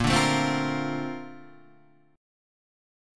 A# Major 9th